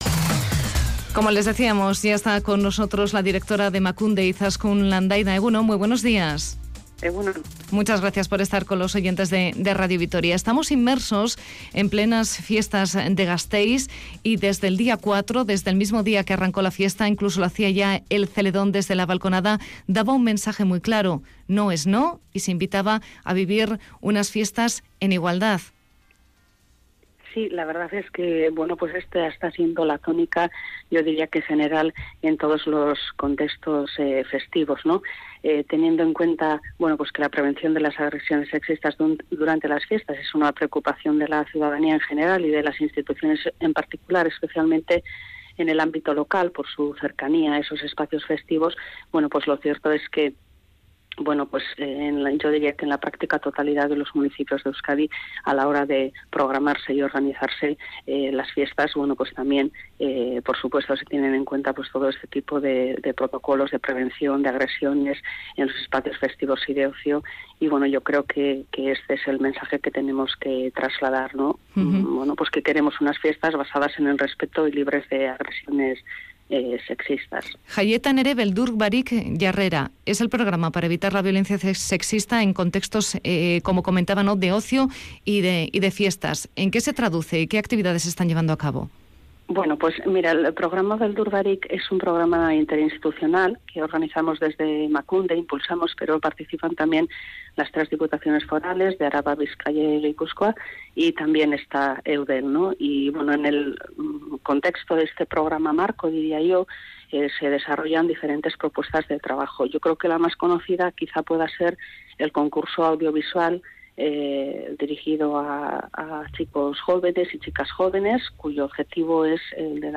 Audio: La directora de Emakunde, Izaskun Landaida, ha estado en la Primera Edición de 'Araba Gaur' hablando sobre las agresiones sexistas.